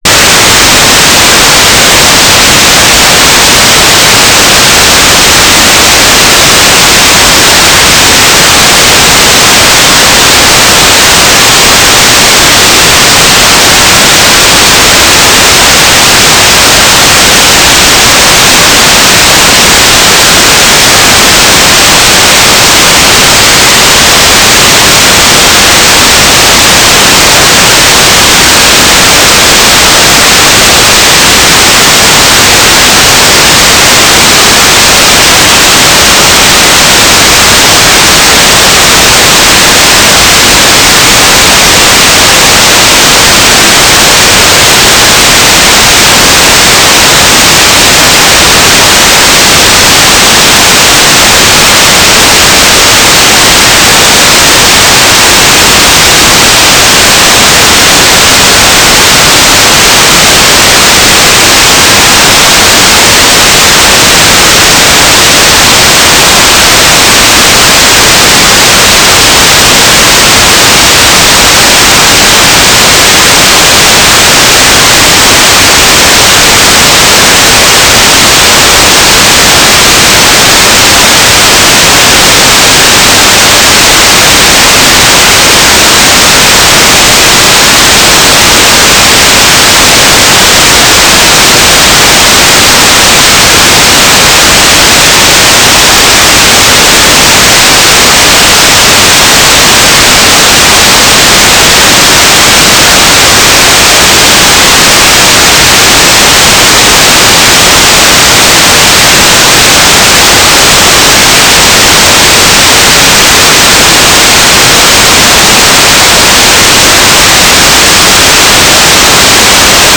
"station_name": "EIRSAT-1 GS - Dún Chaoin",
"transmitter_description": "Mode U - 9k6 Transmitter",
"transmitter_mode": "GMSK",